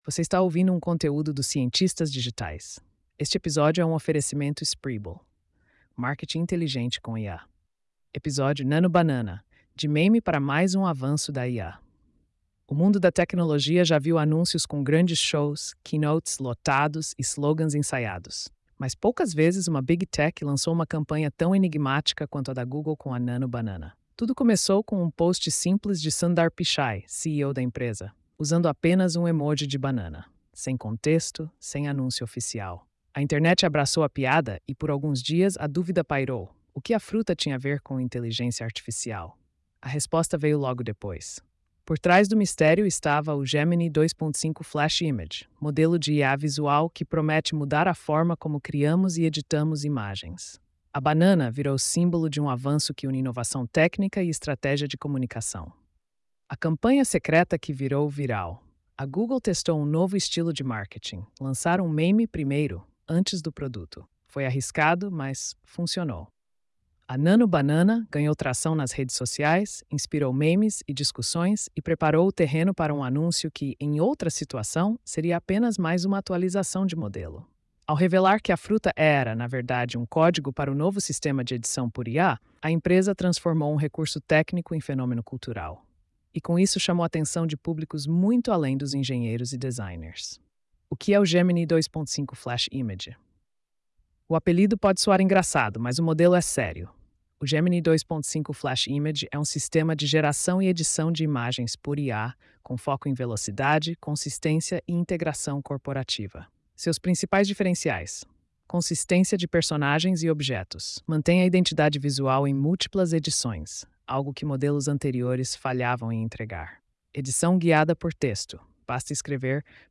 post-4319-tts.mp3